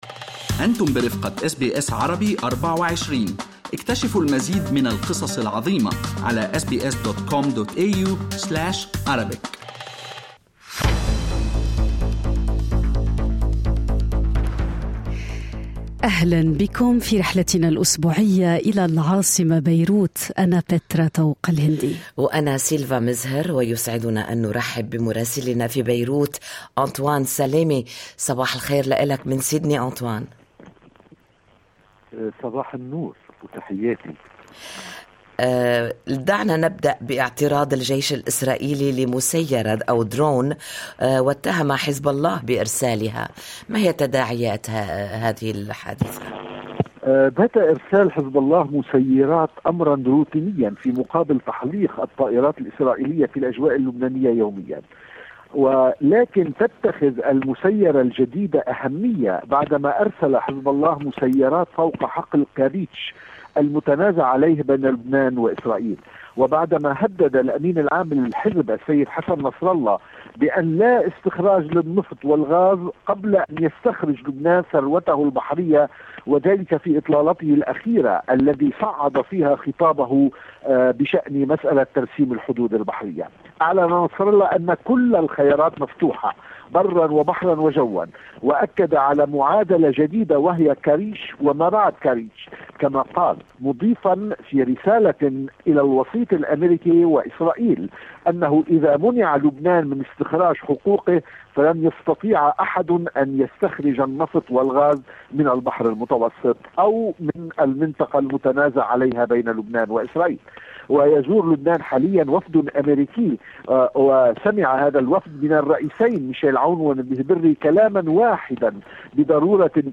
من مراسلينا: أخبار لبنان في أسبوع 19/7/2022